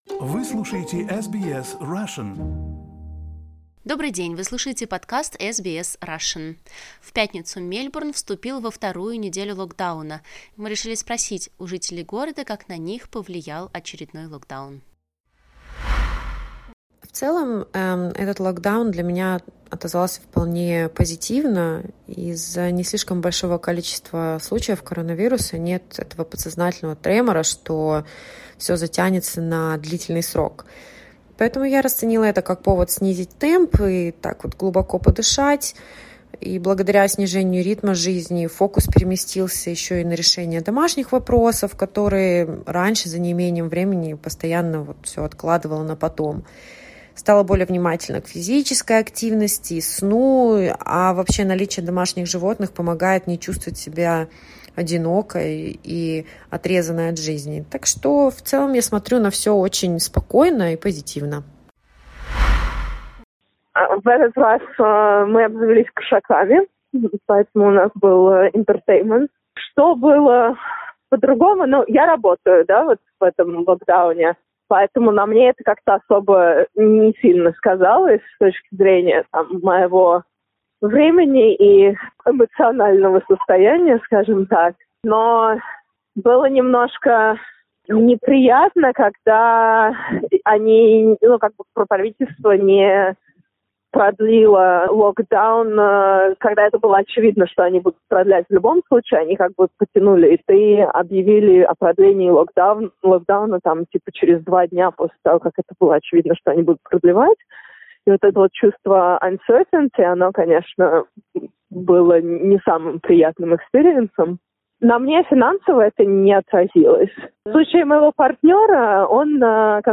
How has the new lockdown in Melbourne influenced you? Vox-pop